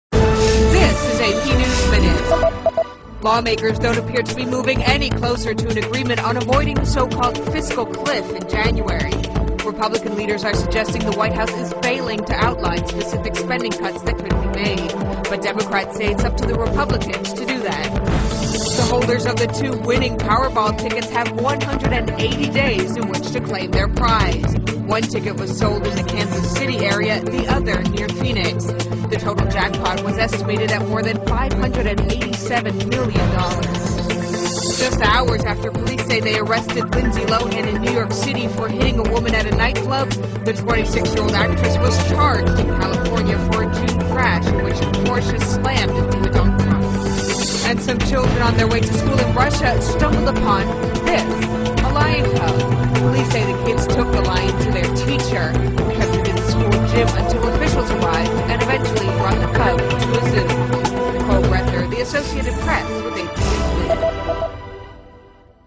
在线英语听力室美联社新闻一分钟 AP 2012-12-03的听力文件下载,美联社新闻一分钟2012,英语听力,英语新闻,英语MP3 由美联社编辑的一分钟国际电视新闻，报道每天发生的重大国际事件。电视新闻片长一分钟，一般包括五个小段，简明扼要，语言规范，便于大家快速了解世界大事。